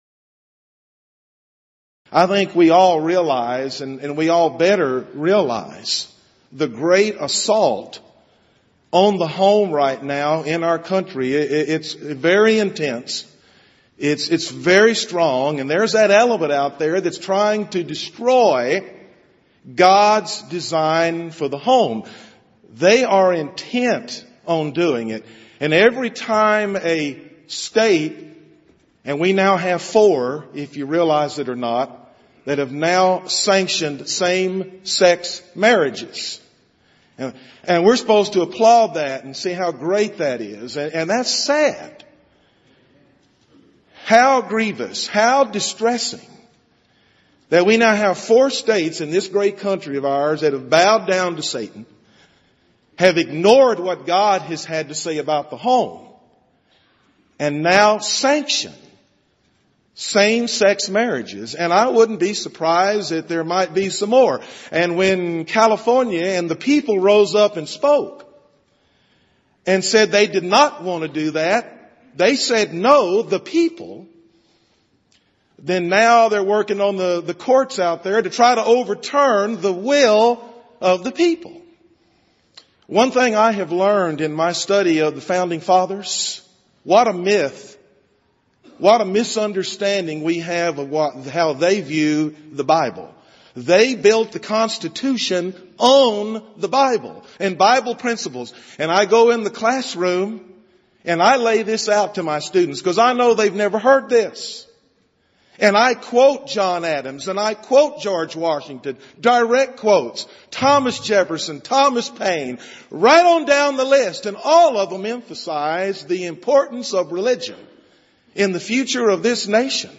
Event: 28th Annual Southwest Lectures Theme/Title: Honoring Christ: Calling For Godly Homes
lecture